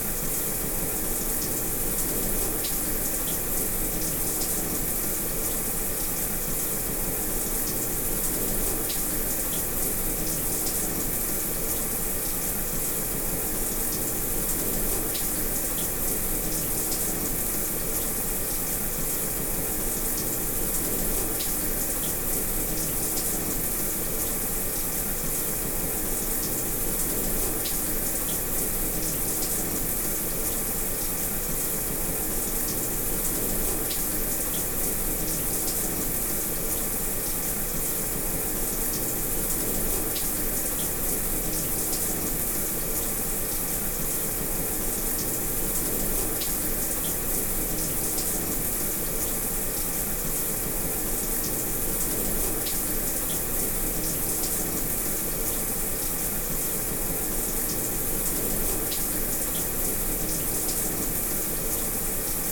ユニットバスシャワー２
hotel_shower2.mp3